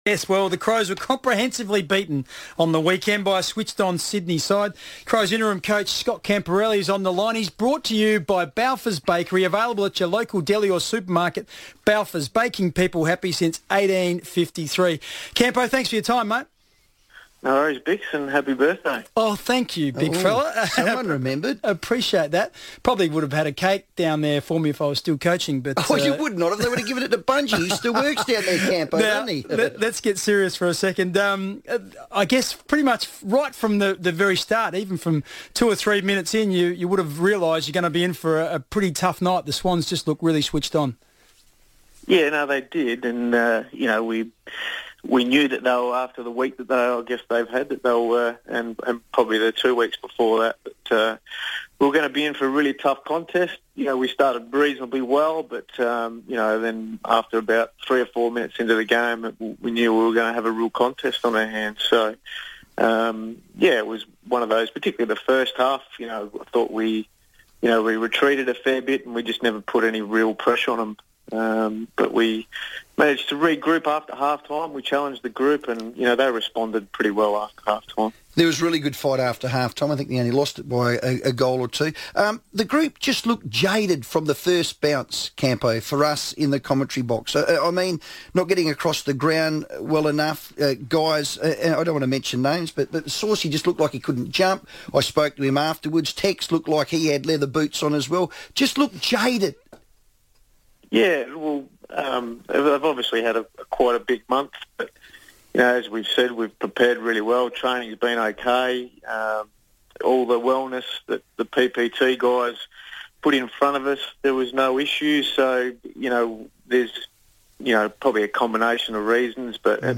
Crows interim Senior Coach Scott Camporeale spoke on the FIVEaa Sports Show ahead of Friday night's huge clash with Richmond